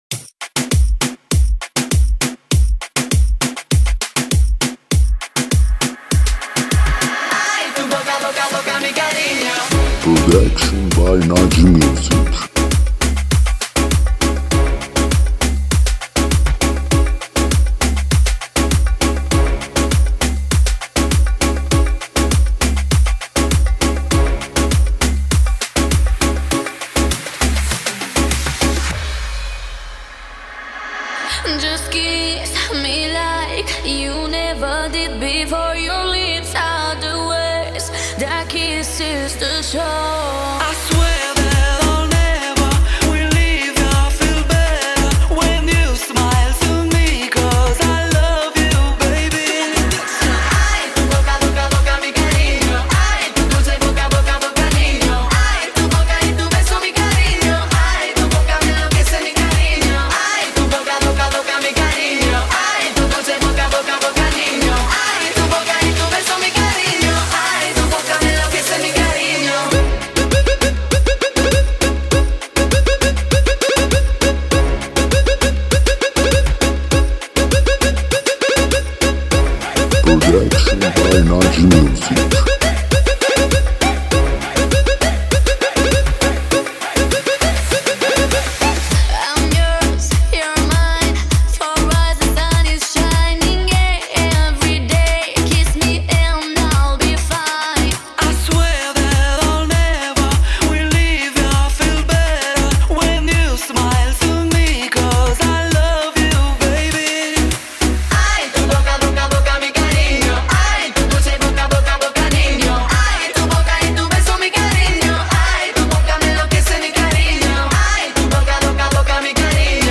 ریمیکس
دانلود ریمیکس شاد اسپانیایی مخصوص دنس و پارتی